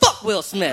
Tags: aziz ansari aziz ansari comedian